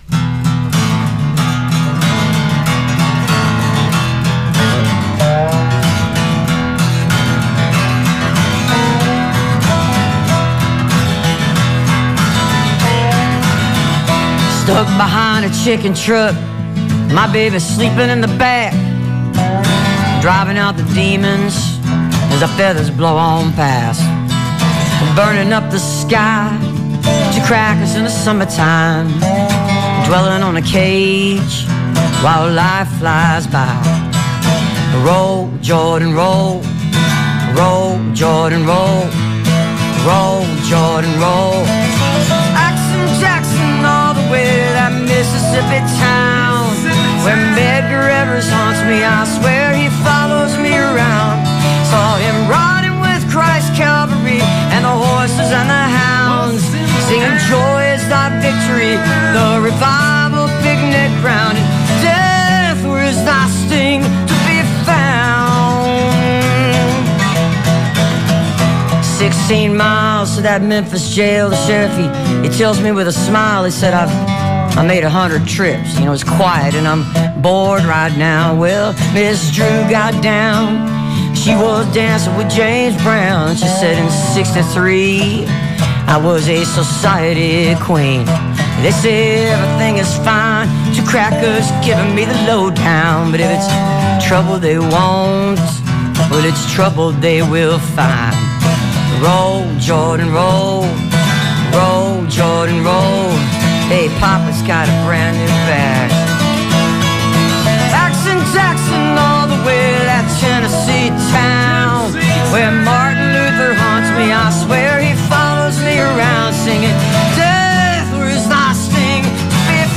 (captured from the live stream)